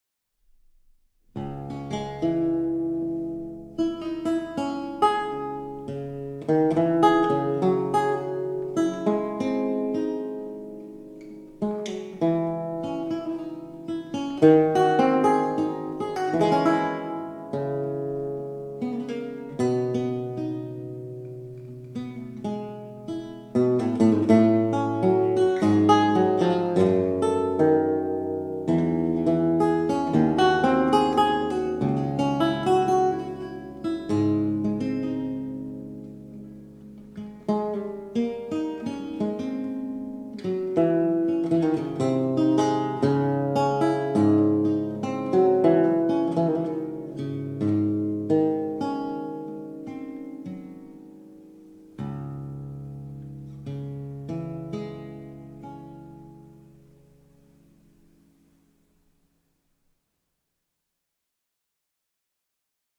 Lute
Houghton Memorial Chapel